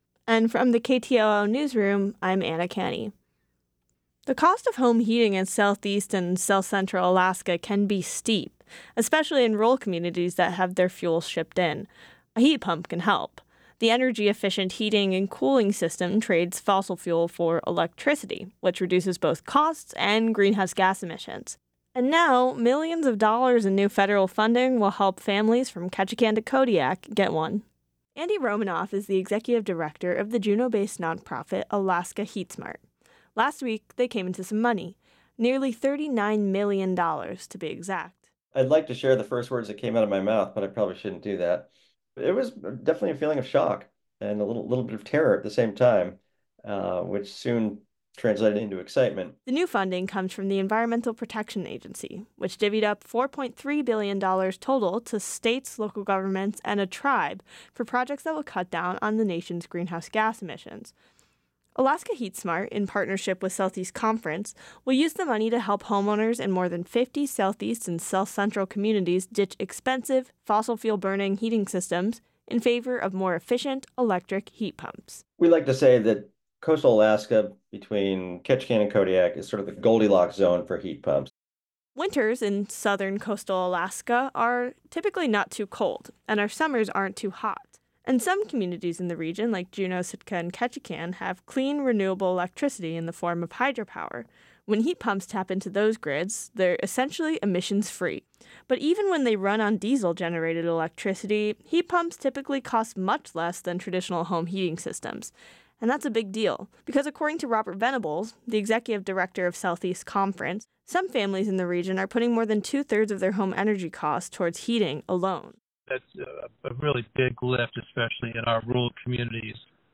Newscast – Monday, July 29, 2024
news-update.wav